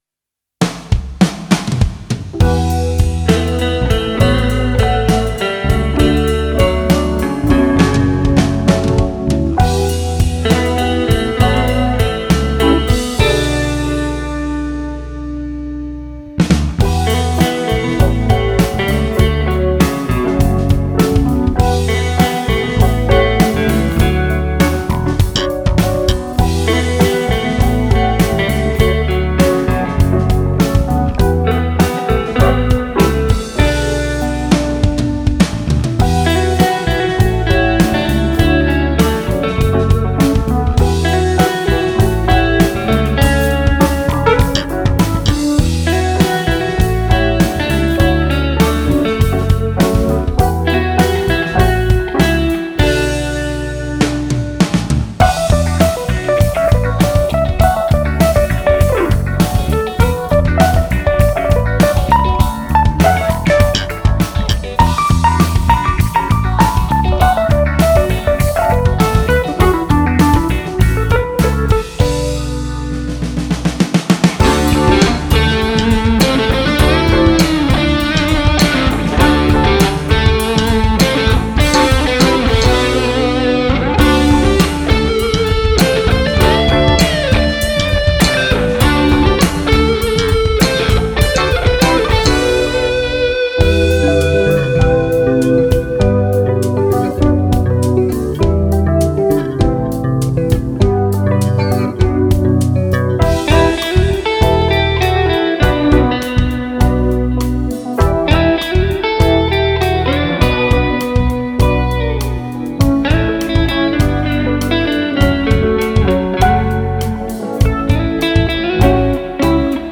Помогите разобраться, почему трек с меньшим луфсом - 01 звучит громче чем - 02? АЧХ и др. примерно одинаковые.